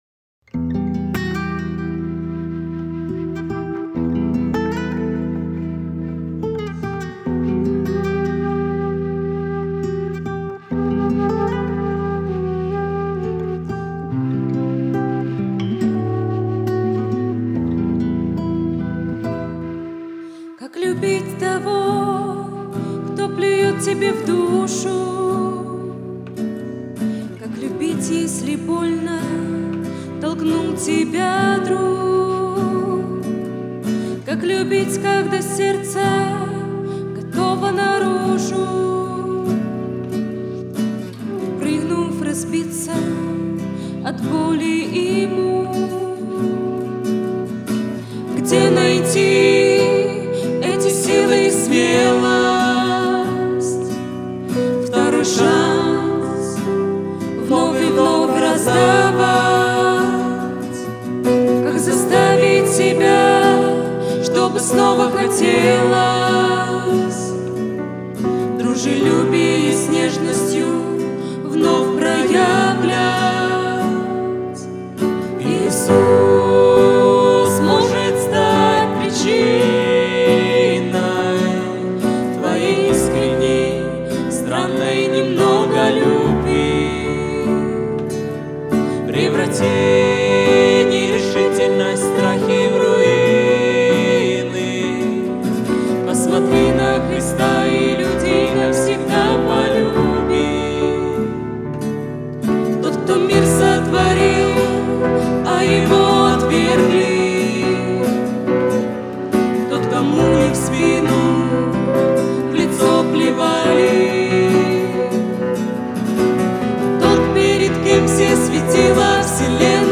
281 просмотр 121 прослушиваний 4 скачивания BPM: 70